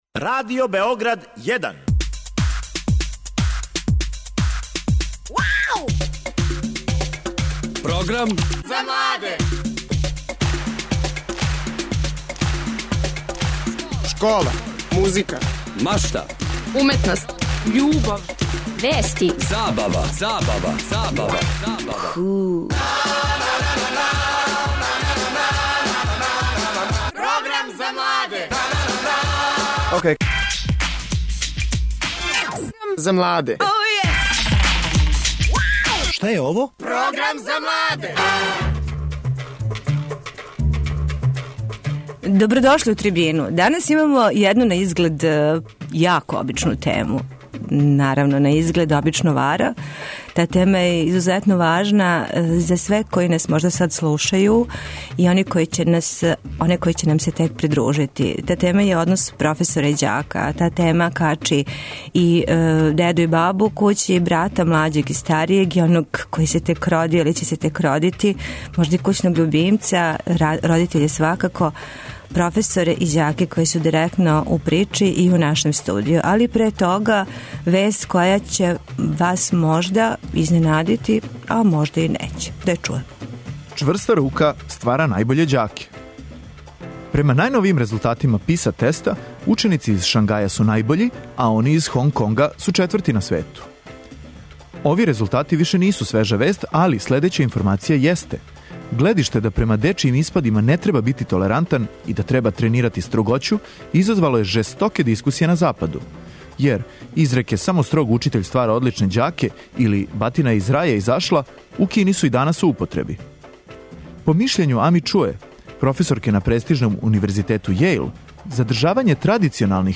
Ову информацију претворићемо у питање, које ћемо упутити гостима Трибине: професорима и ученицима, јер је тема емисије њихов однос. Шта мисле једни о другима, колико једни друге поштују, откуд насиље, сукоби, неразумевање...